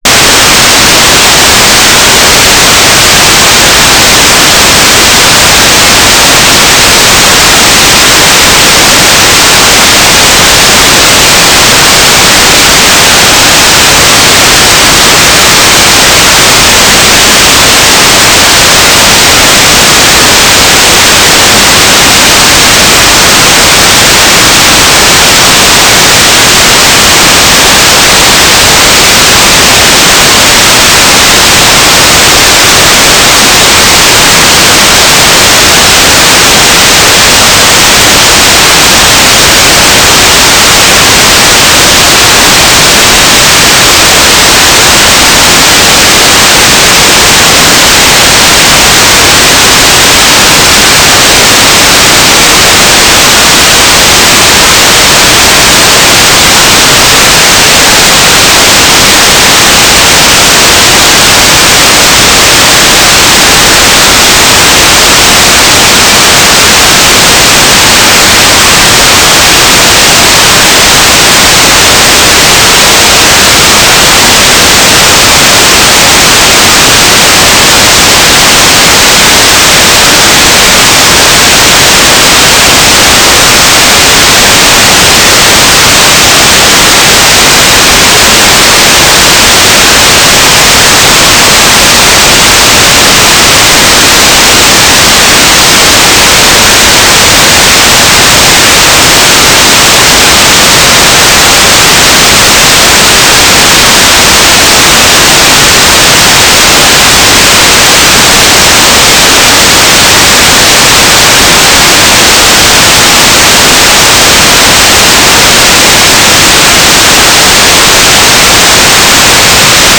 "transmitter_description": "AX.25 beacon",
"transmitter_mode": "FSK",